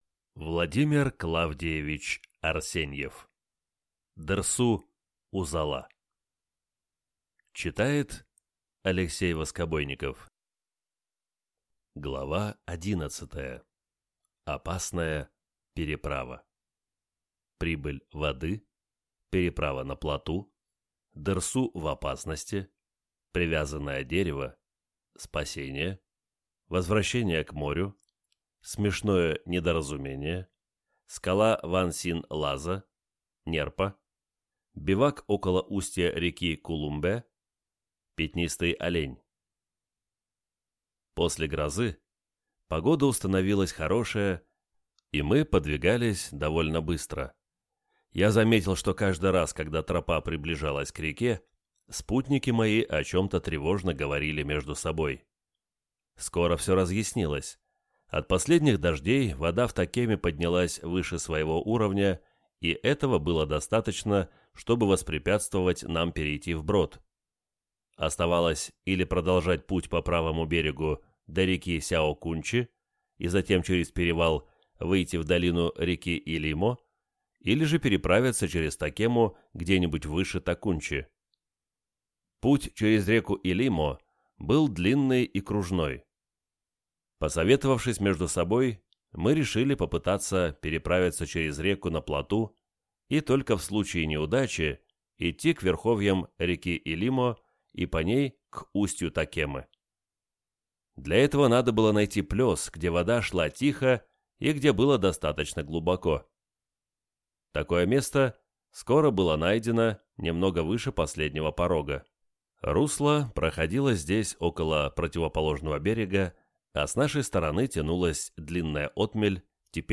Аудиокнига Дерсу Узала | Библиотека аудиокниг
Прослушать и бесплатно скачать фрагмент аудиокниги